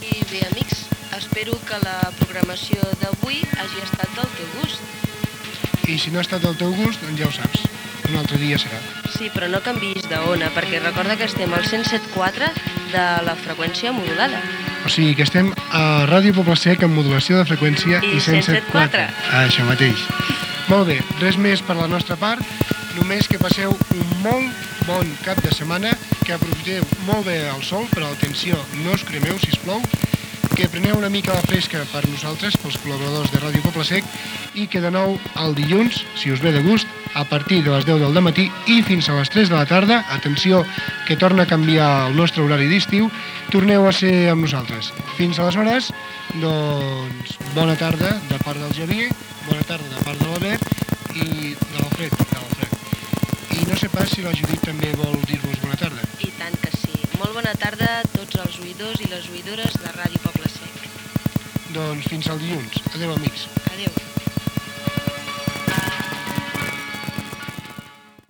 d5aff333776cdb1d2c674f3f5ea44900f8ced9b0.mp3 Títol Ràdio Poble-sec Emissora Ràdio Poble-sec Titularitat Tercer sector Tercer sector Barri o districte Descripció Comiat d'un programa d'estiu amb identificació de l'emissora.